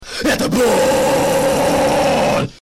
Будет вам ХАРДКОР